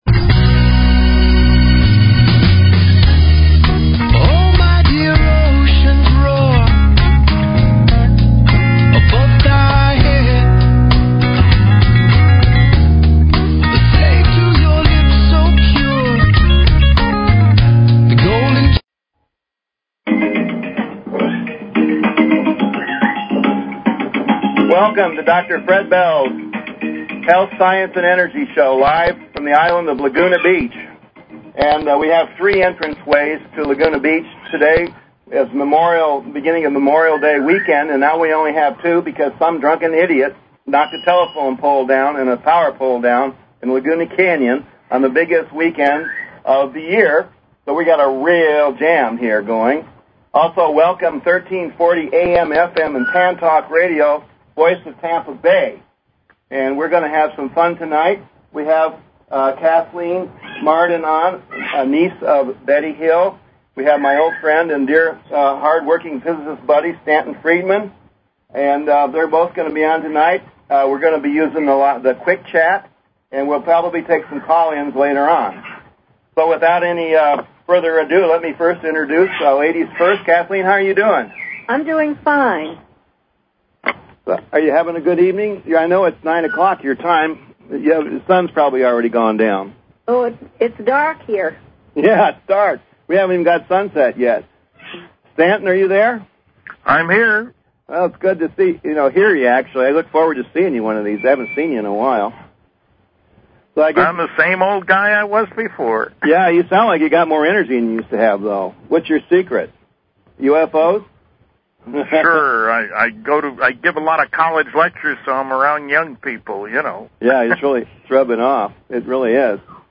Talk Show Episode, Audio Podcast, Dr_Bells_Health_Science_and_Energy_Show and Courtesy of BBS Radio on , show guests , about , categorized as